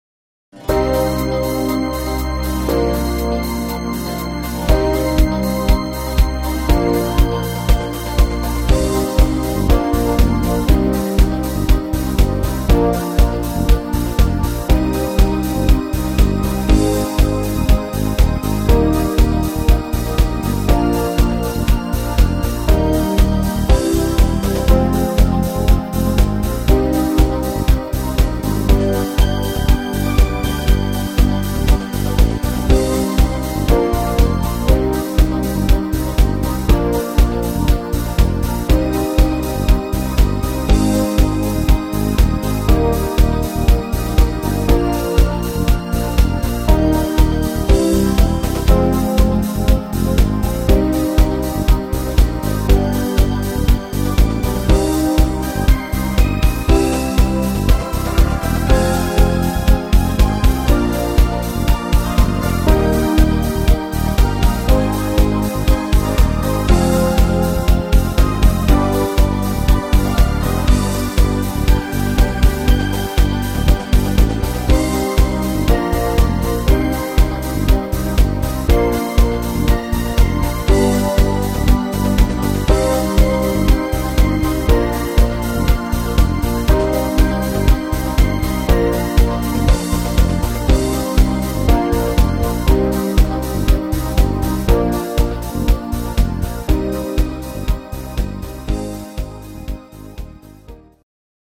instr. Klarinette+Gitarre